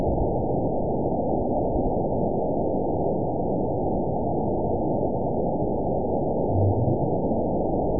event 920462 date 03/26/24 time 20:13:34 GMT (1 month ago) score 9.20 location TSS-AB02 detected by nrw target species NRW annotations +NRW Spectrogram: Frequency (kHz) vs. Time (s) audio not available .wav